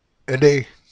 • When the letter R is used between two vowels or following another consonant in the same syllable, the sound is achieved with the same position of the tongue but with a simple or soft vibration: